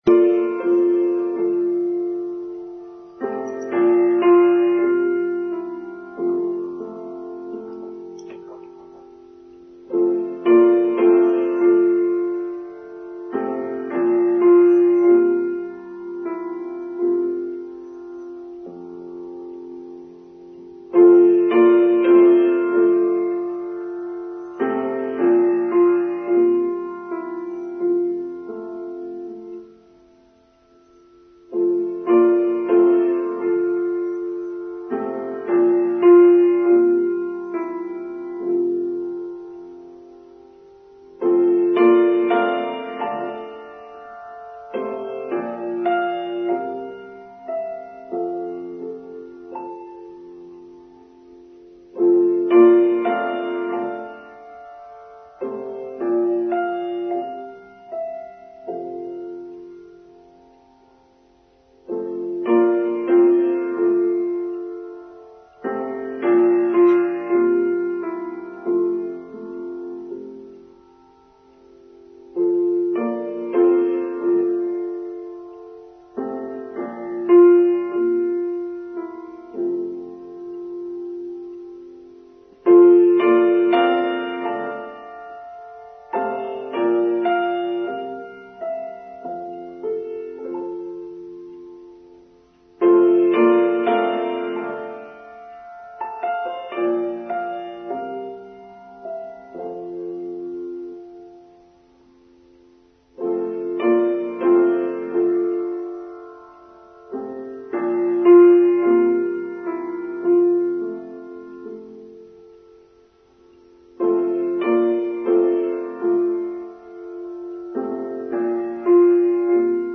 Crossing a New Threshold: Online Service for Sunday 10th September 2023